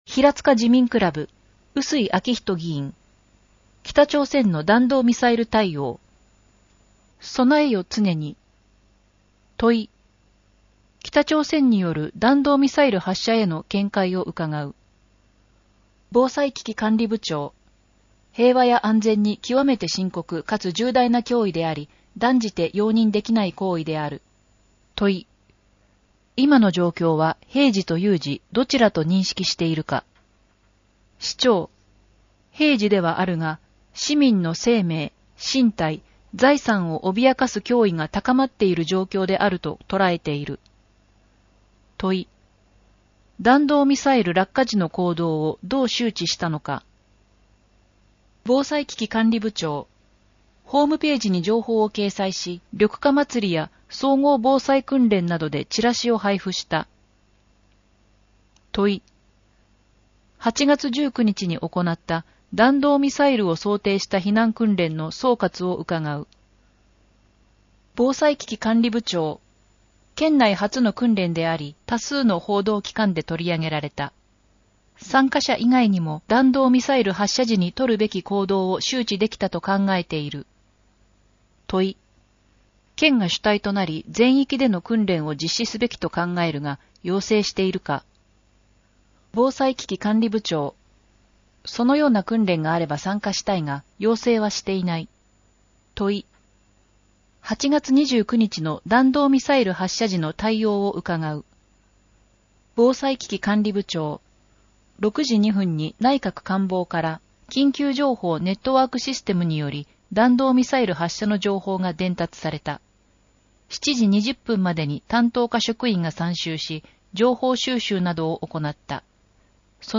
平塚市議会では目の不自由な方に、ひらつか議会だよりを音声化した「声の議会だより」と、掲載記事を抜粋した「点字版議会だより」をご用意しています。
「声の議会だより」は平塚市社会福祉協議会と平塚音訳赤十字奉仕団の協力により作成しています。